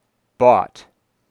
ɔ
[4] Bought has [ɔ] only in some American dialects; many speakers have no [ɔ]; they use [ɑ] in bought and similar words.